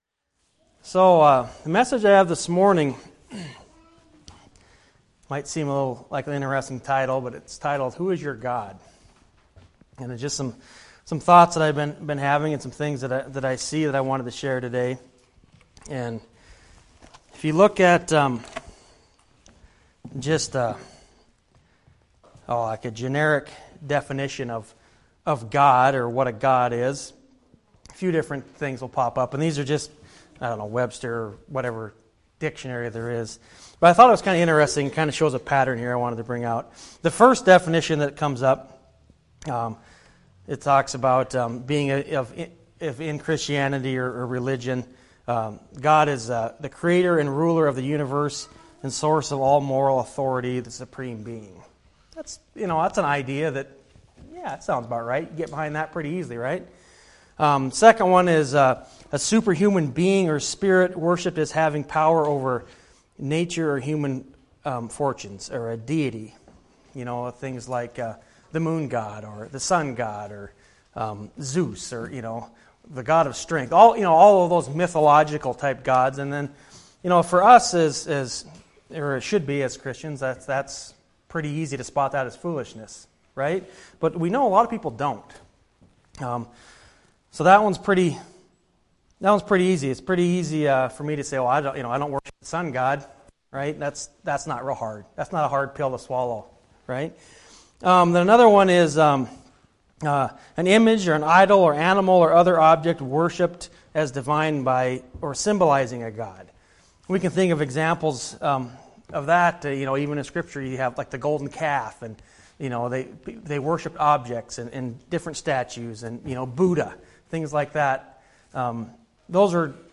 Series: Sermon